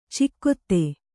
♪ cikkotte